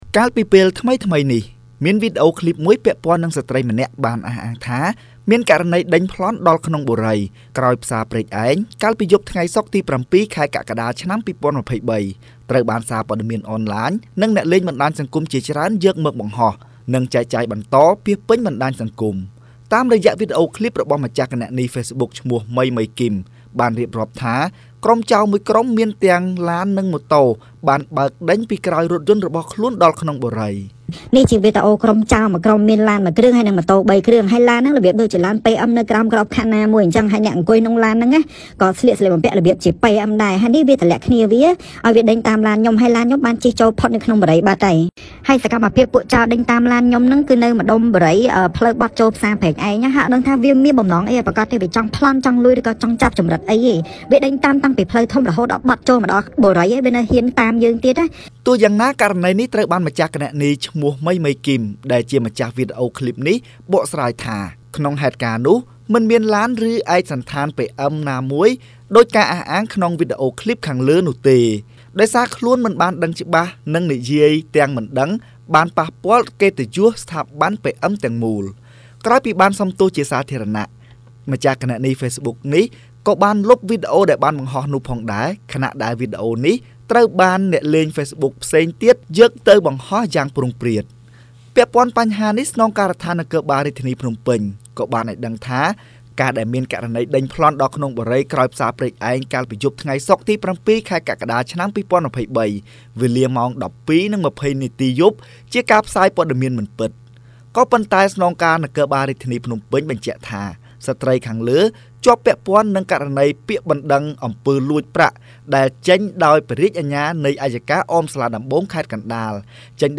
រាយការណ៍